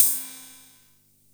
Index of /musicradar/essential-drumkit-samples/Vintage Drumbox Kit
Vintage Cymbal 03.wav